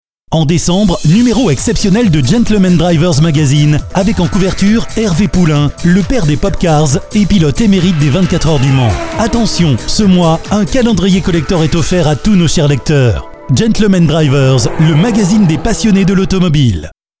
Pymprod réalise pour vous des spots publicitaires avec nos voix off masculines et féminines.
Ils valent bien un spot publicitaire promotionnel.